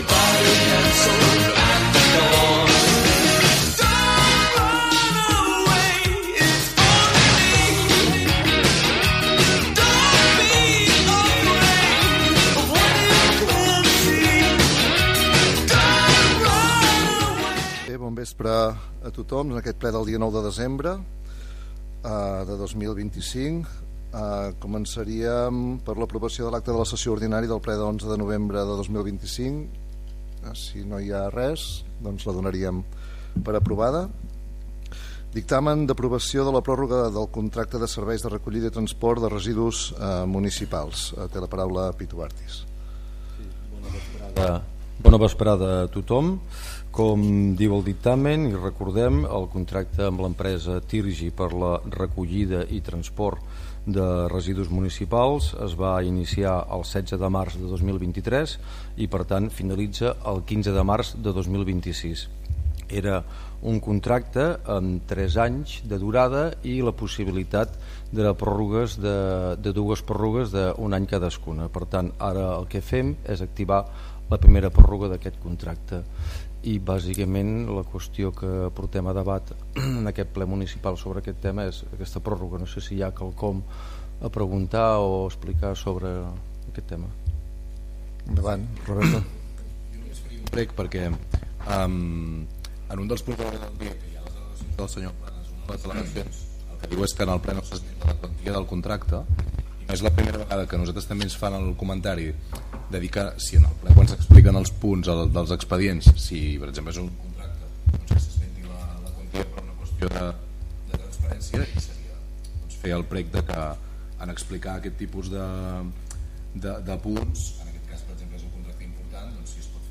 Tema musical, inici del Ple Municipal de l'Ajuntament de Celrà